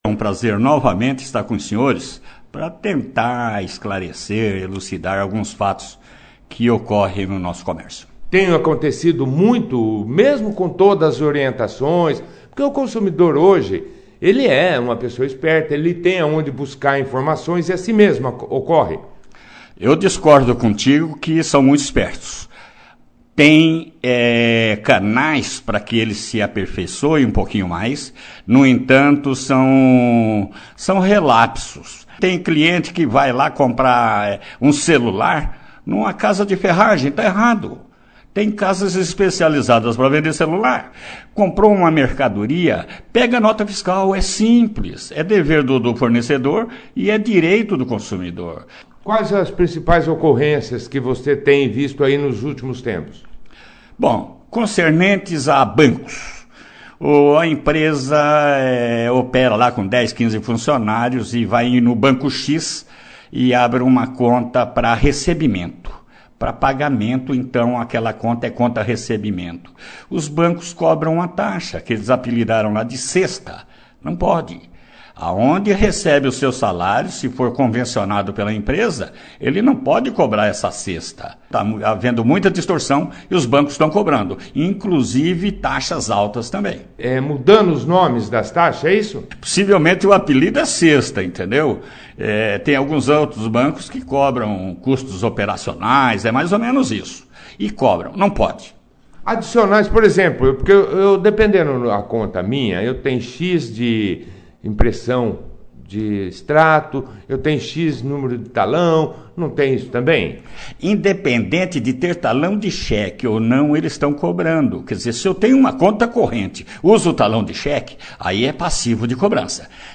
O coordenador do Procon – Defesa do Consumidor – de Bandeirantes, Dilnei Gomes Spindola, participou da 1ª edição do jornal Operação Cidade, desta terça-feira,02/07/19, fazendo alguns esclarecimentos ao consumidor na hora da compra, conta recebimento, empréstimos consignados, cartões de credito, filas em bancos, telefonia, entre outros.